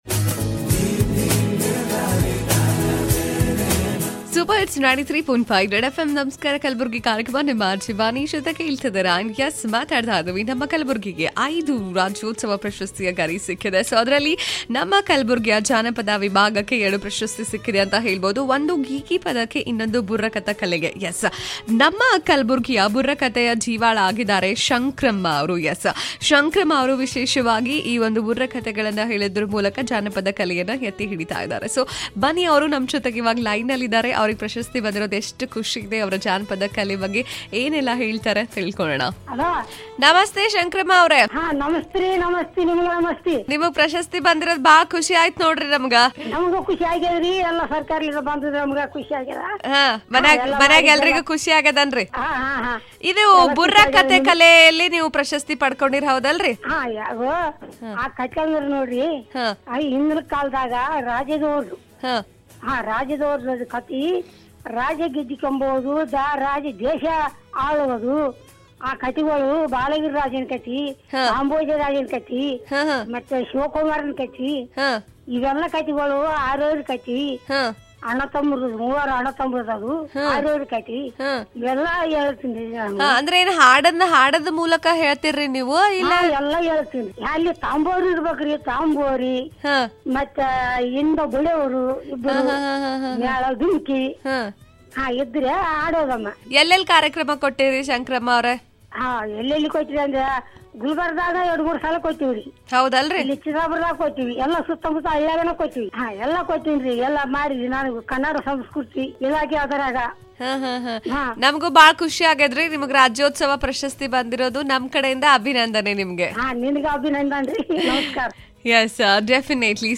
Janapada vibhaga Burkatha kale,.